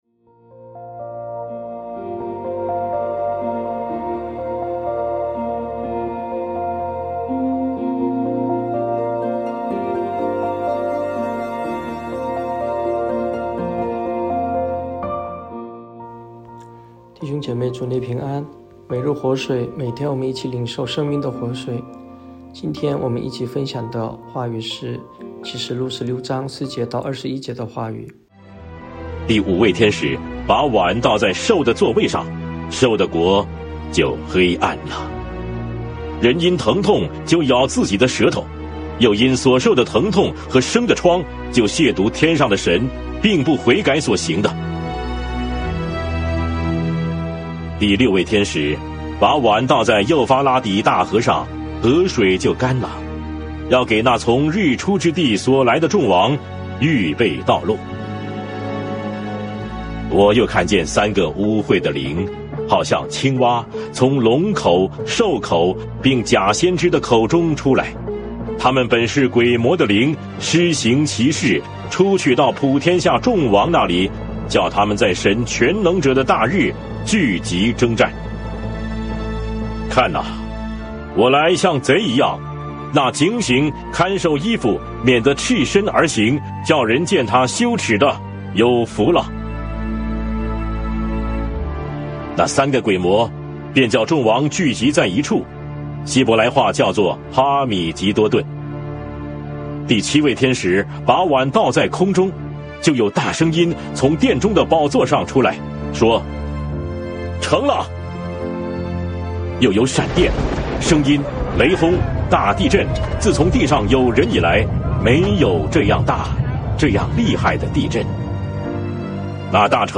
牧/者分享